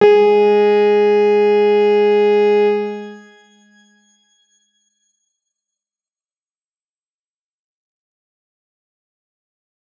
X_Grain-G#3-pp.wav